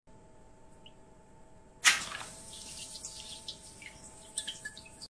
Satisfying AI ASMR Pimple Popper!